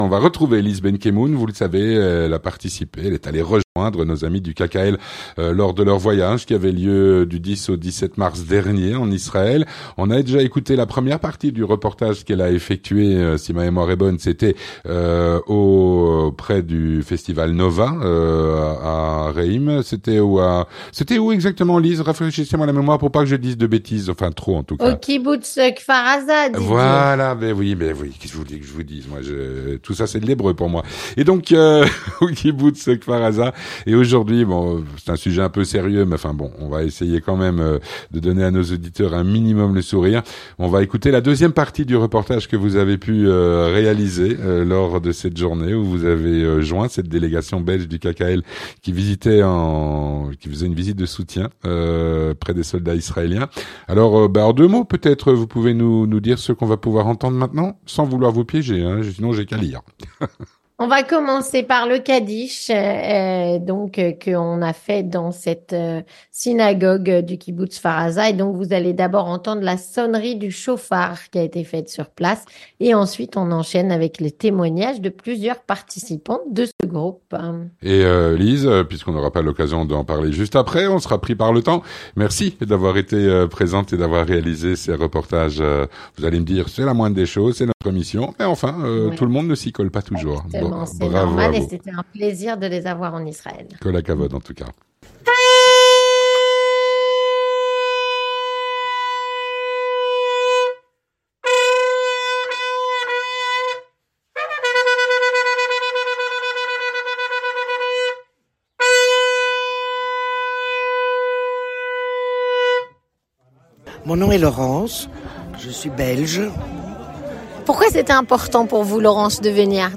Le voyage de solidarité du KKL en Israël s’est terminé dimanche dernier, il a été l’occasion entre autres d’une visite à Kfar aza, d’un hommage et d’un kaddish émouvant dans la synagogue du kibboutz martyr. Voici la seconde partie du reportage réalisé à cvette occasion.
Avec la sonnerie du chofar sur place et on enchaîne avec les témoignages de plusieurs participants.